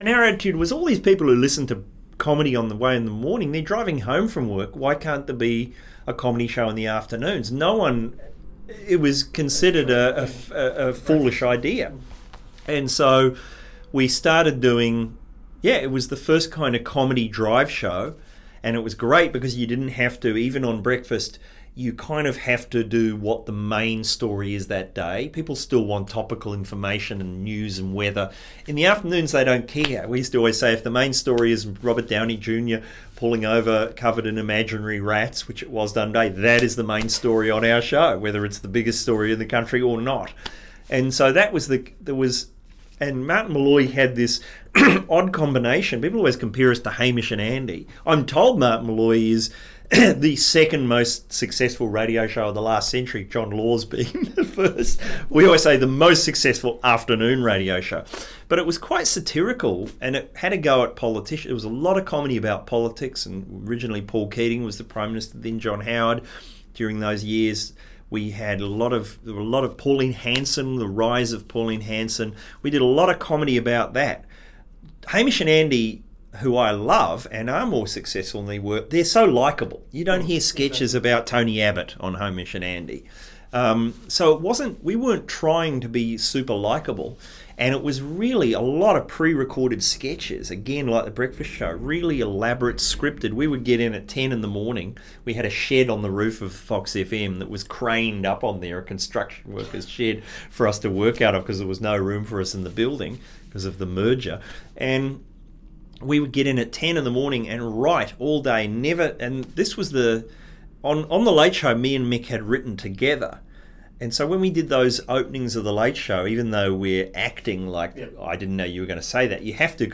Tony Martin oral history: The style of Martin-Molloy